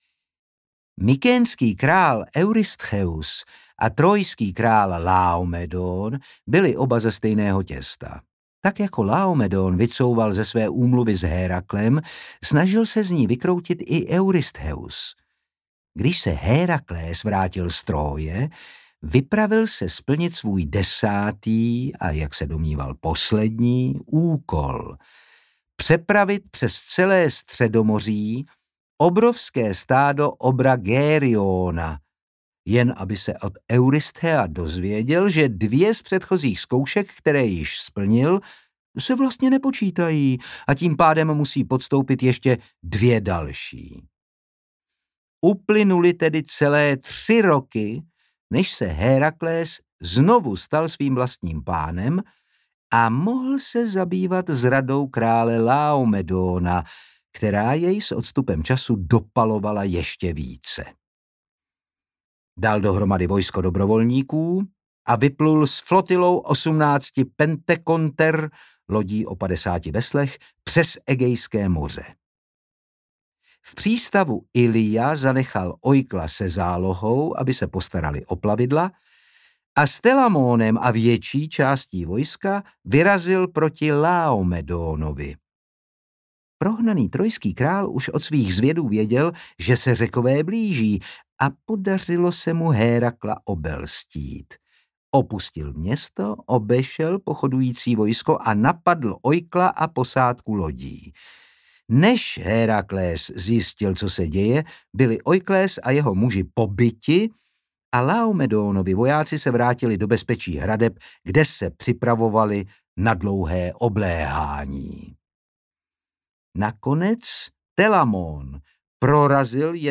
Audiokniha Trója - Stephen Fry | ProgresGuru
Načítám ukázku 0:00 Poslechnout delší ukázku (11 min) Přidat do wishlistu audiokniha Historie Příběhy Autor: Stephen Fry Čte: Otakar Brousek ml.